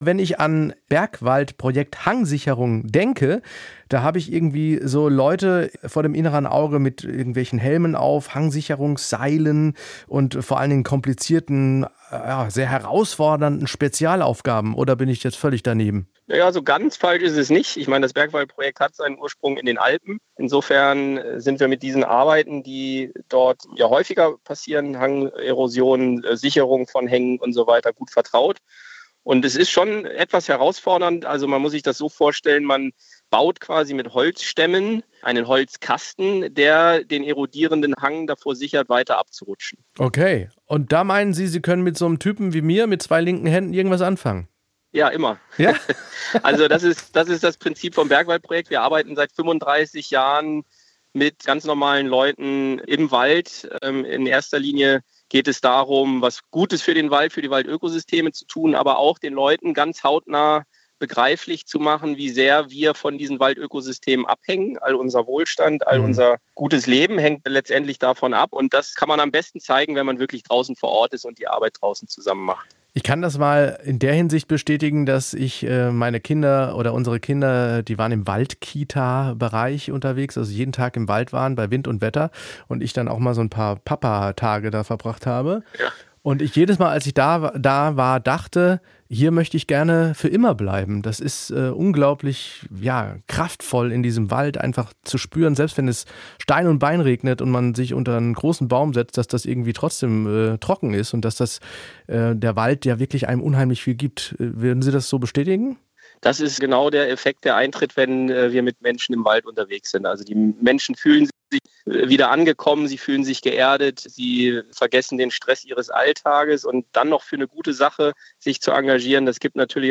Mehr SWR1 Interviews